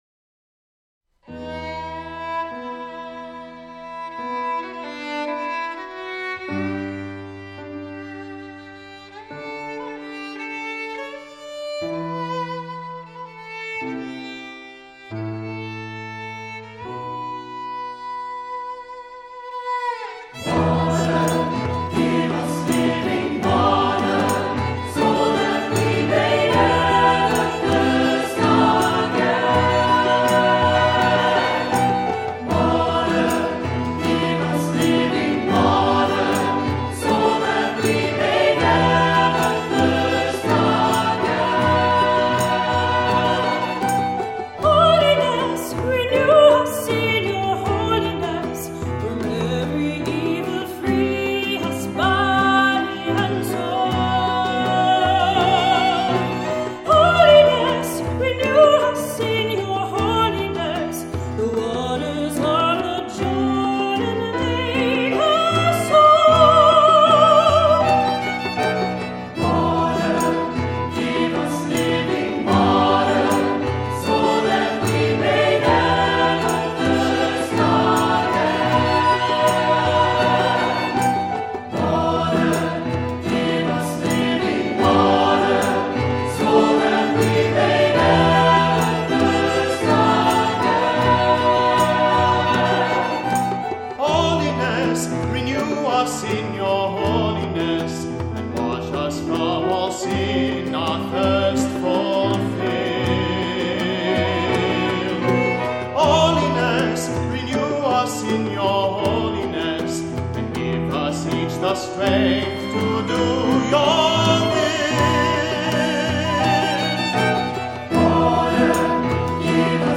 Voicing: SATB; Solo; Cantor; Assembly